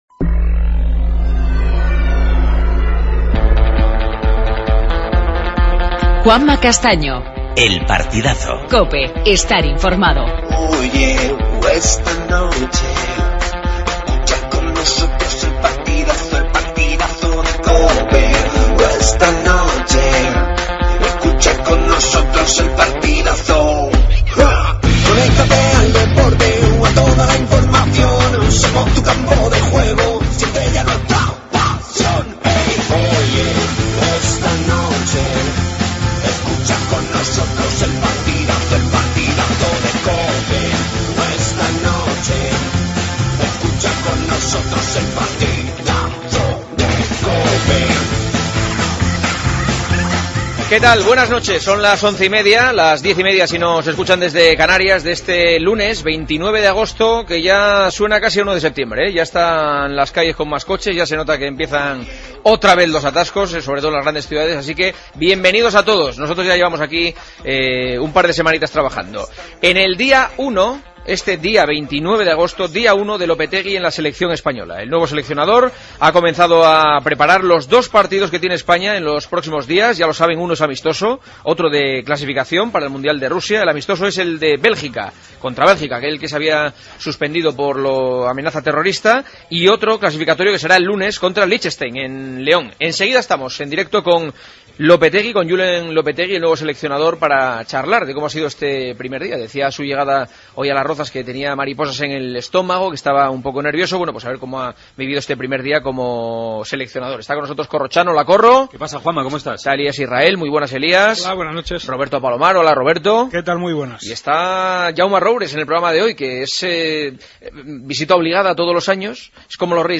AUDIO: Titulares. Messi solo jugará con Argentina si se encuentra al 100% físicamente.